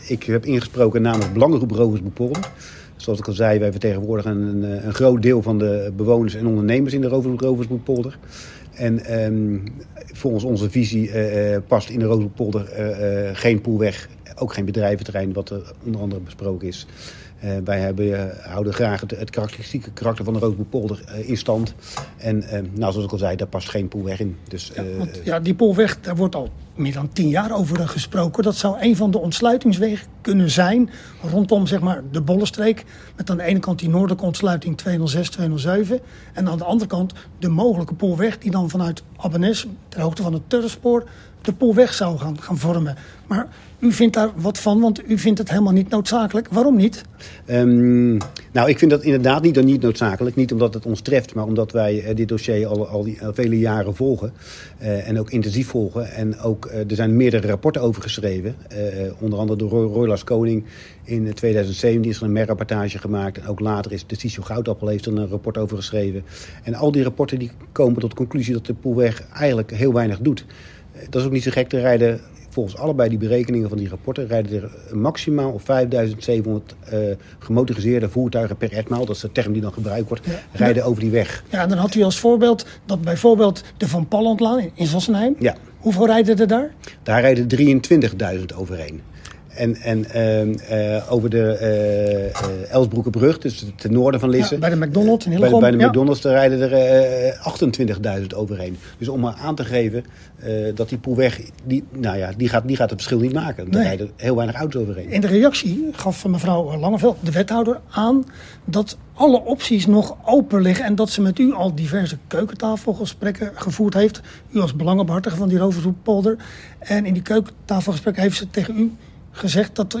Lisse – De toekomst van Lisse tot 2040 stond donderdag op de agenda van de raadsvergadering.